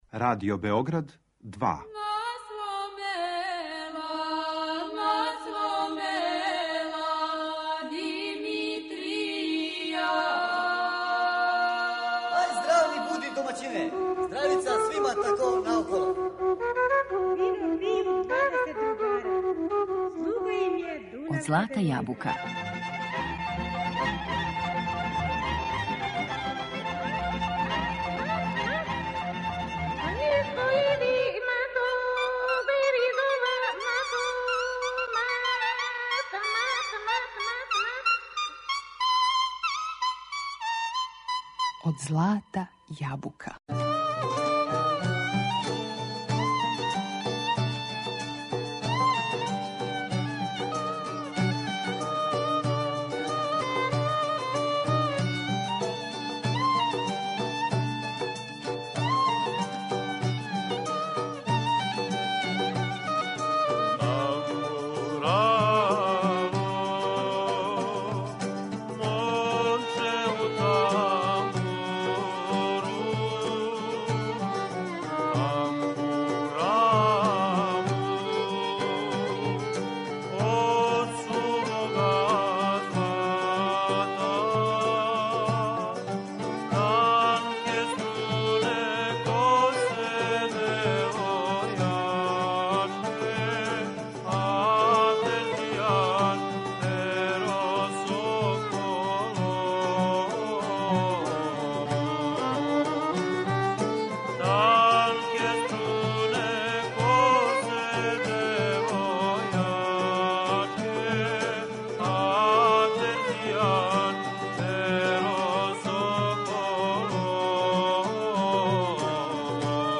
Tambura
Preskočićemo uobičajeni uvod koji podrazumeva poreklo, razvoj instrumenta i najistaknutije kompozitore i vođe tamburaških sastava da bi se prepustili slušanju zvuka ovog i srodnih mu instrumenata, ulogu u različitim formacijama što u privatnoj, što u javnoj sferi, a najviše savremenog muzičkog izvođenja.